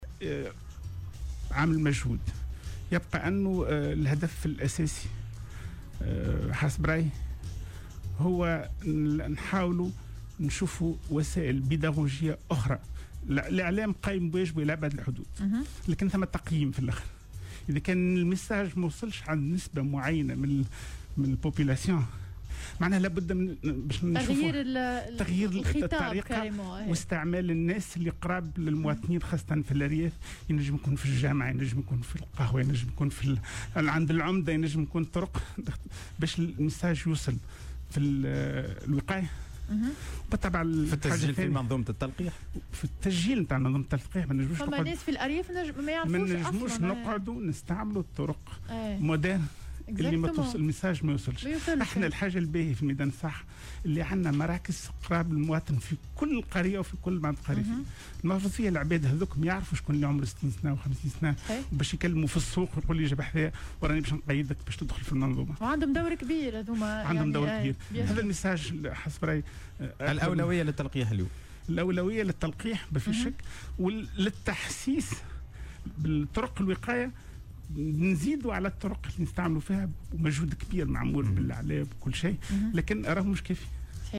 ودعا في مداخلة له اليوم على "الجوهرة أف أم" إلى ضرورة البحث عن وسائل بيداغوجية أخرى خلافا للدور الهام الذي يقوم به الإعلام والاقتراب أكثر من متساكني الأرياف و المناطق النائية.